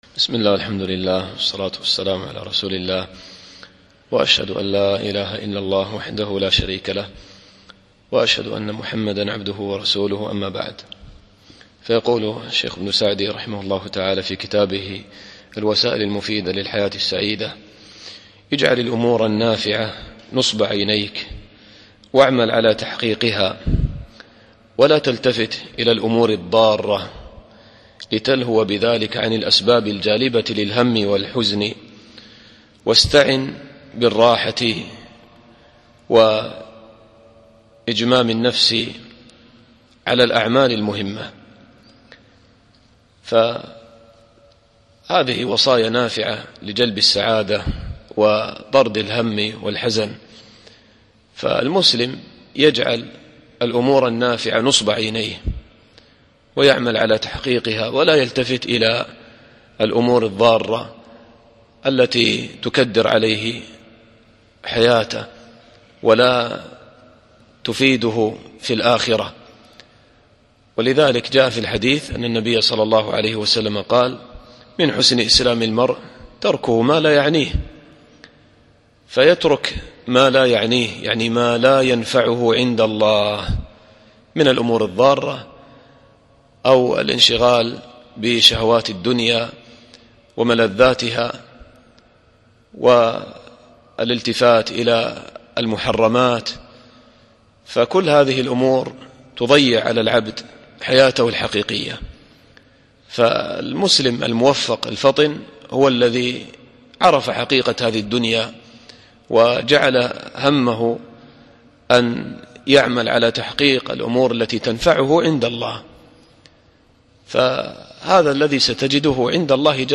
الدرس الثاني والعشرون